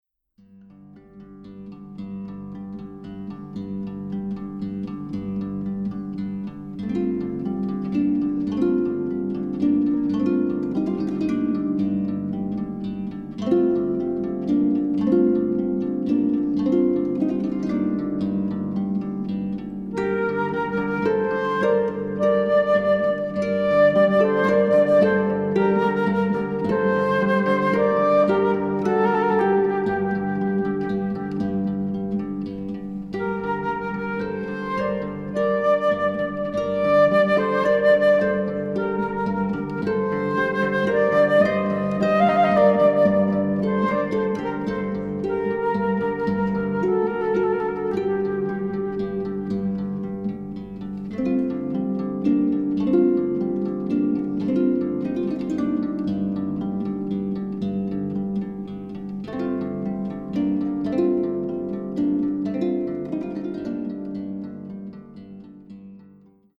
Australian classical music
Classical